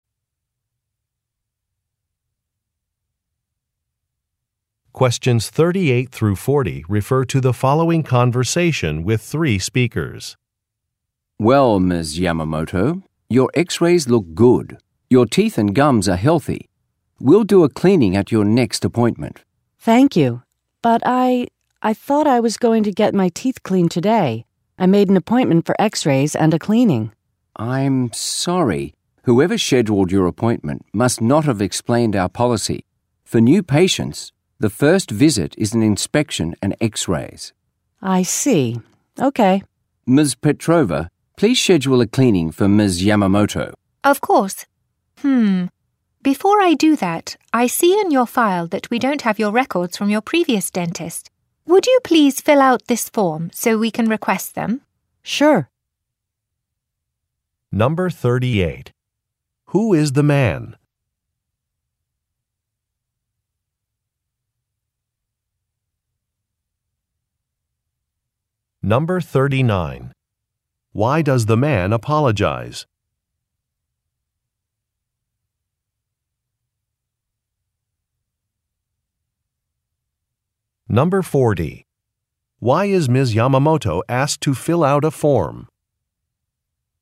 Question 38 - 40 refer to following conversation: